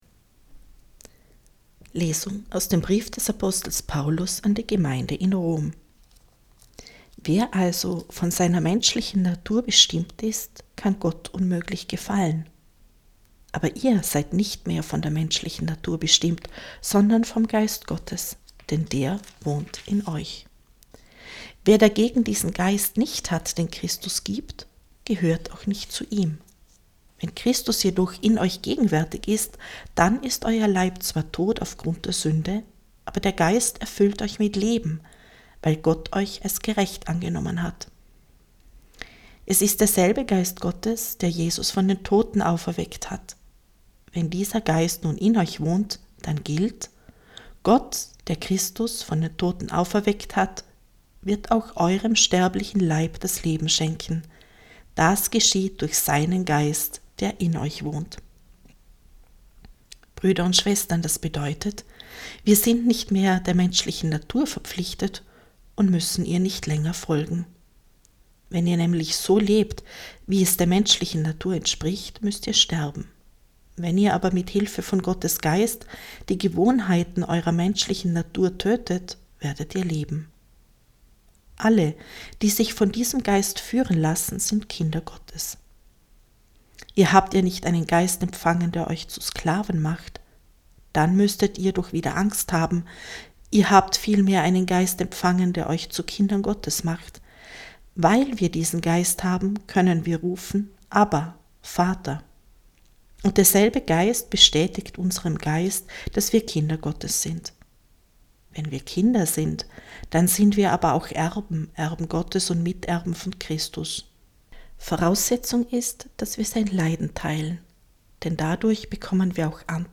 Wenn Sie den Text der 2. Lesung aus dem Brief des Apostels Paulus an die Gemeinde in Rom anhören möchten: